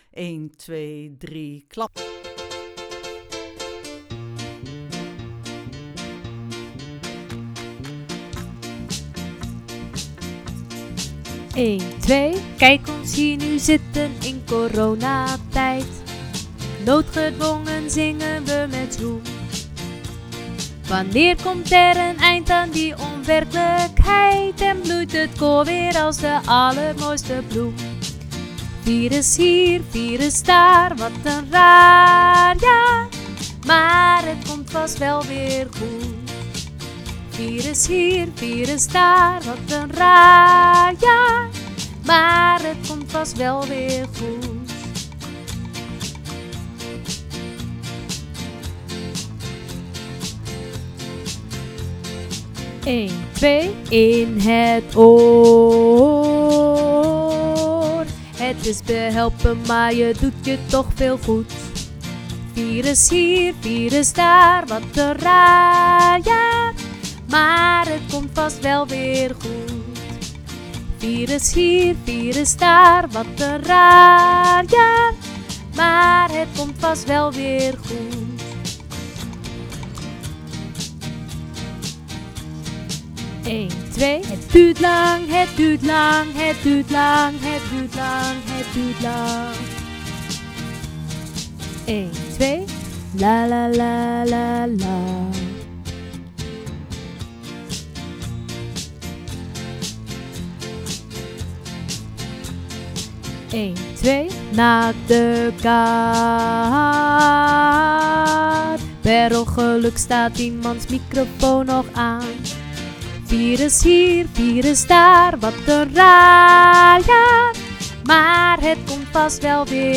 Coronalied-Alt-met-inzetten-aftellen-1.wav